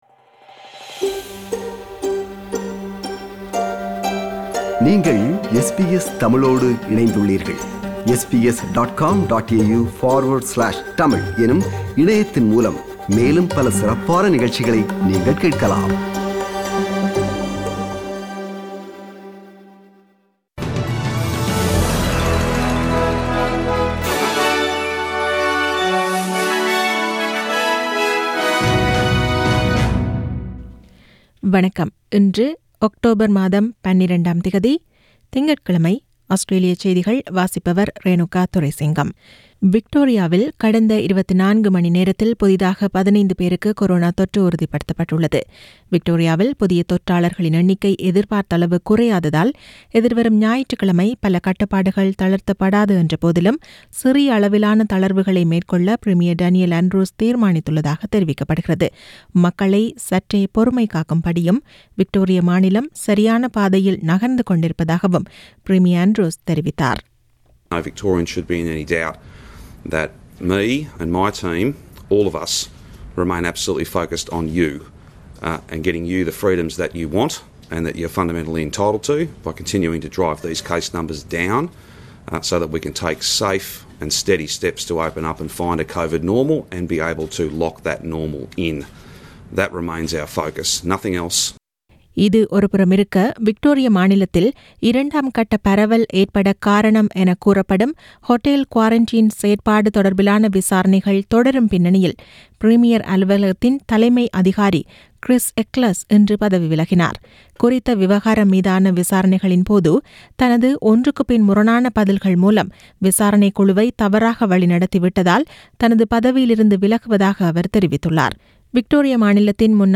Australian news bulletin for Monday 12 October 2020.